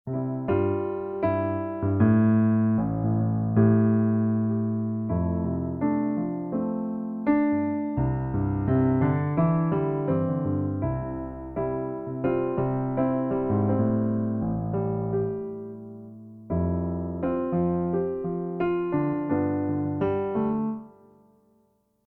Anhänge Midi Piano - MAIN.mp3 Midi Piano - MAIN.mp3 665,8 KB · Aufrufe: 9.380 Midi Piano - Tempo Map.mid Midi Piano - Tempo Map.mid 955 Bytes · Aufrufe: 237
Hier ein Beispiel: Das ist eine eins zu eins Umwandlung des Audiofiles ohne Nachbearbeitung. Wie man hört, ist es nicht hundertpotent.